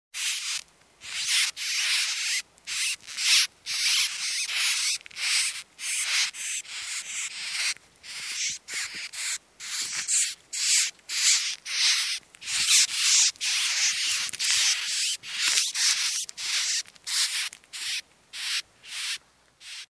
Звуки мышей
Шуршание мышиных лапок